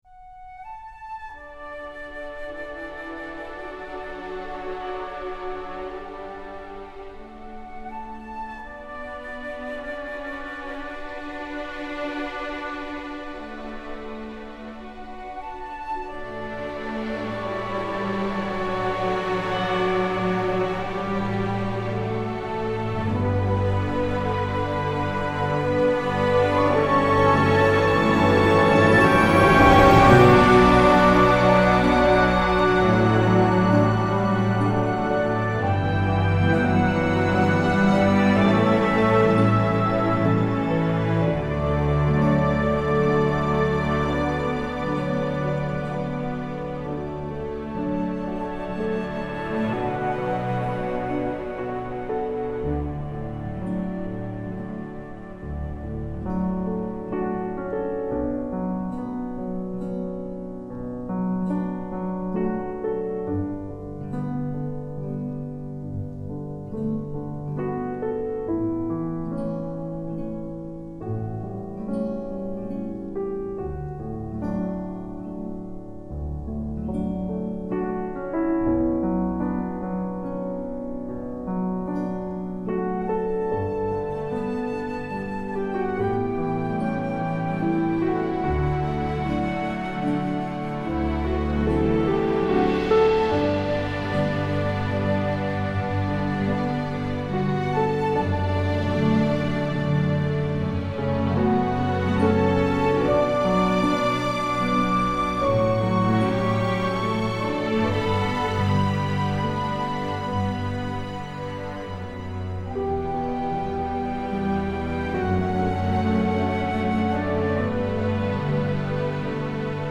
A symphonic poem
historical epic, orchestral, western, romantic